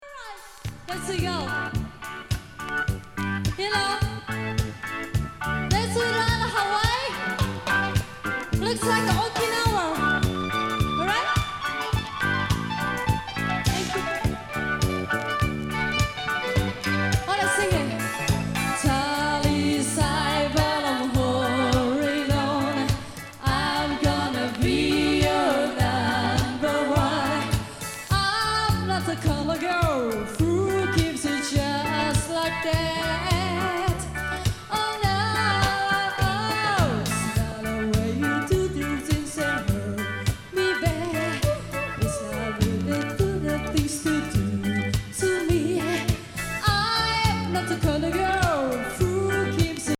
８１年ライブ